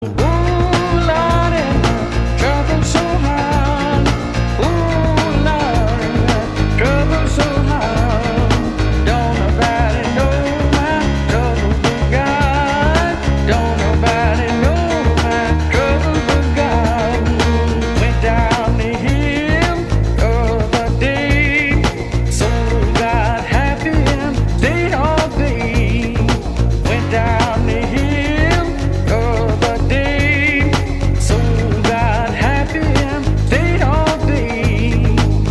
• Качество: 128, Stereo
грустные
спокойные